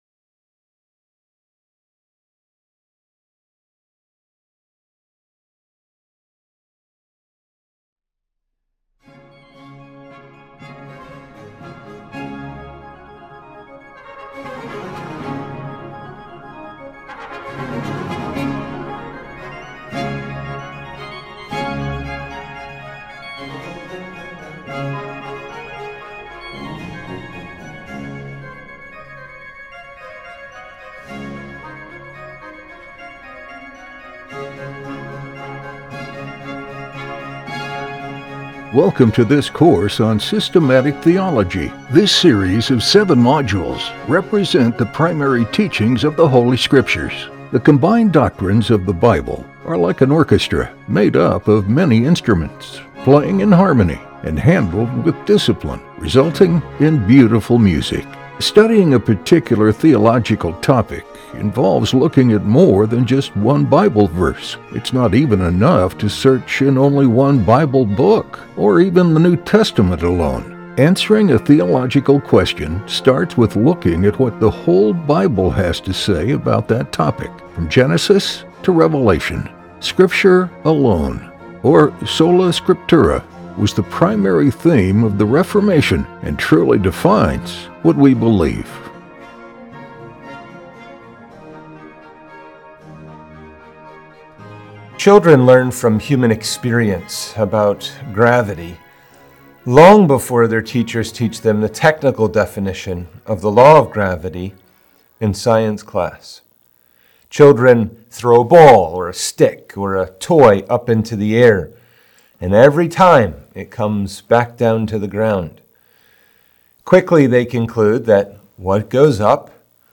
We now turn to consider other important doctrinal truths related to Christ’s incarnation. In this lecture, we will explore Christ’s states of humiliation and exaltation.